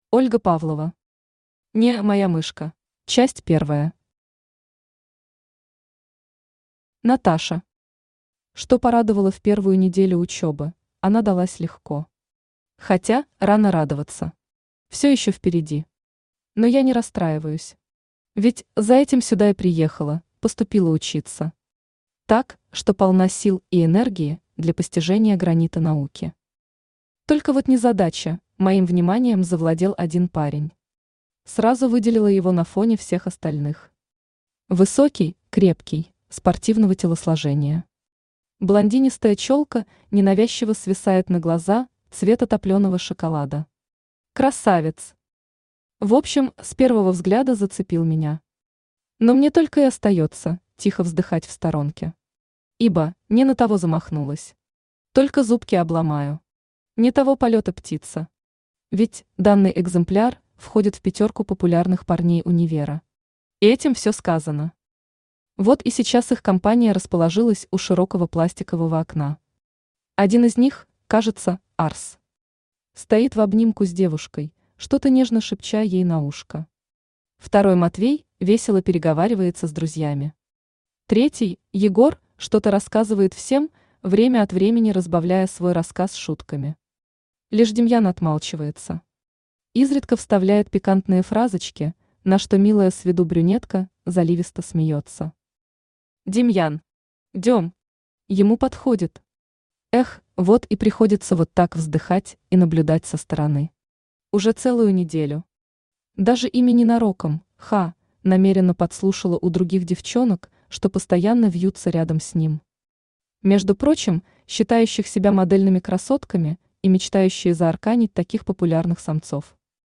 Аудиокнига (не)моя мышка | Библиотека аудиокниг
Aудиокнига (не)моя мышка Автор Ольга Анатольевна Павлова Читает аудиокнигу Авточтец ЛитРес.